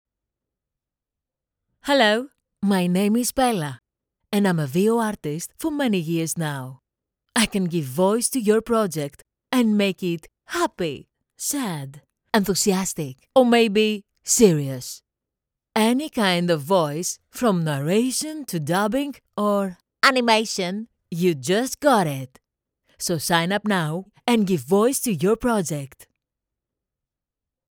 Kommerzielle Demo
Junger Erwachsener
Tonhöhe
Tief
KlarOptimistischNatürlichFreundlichNeutral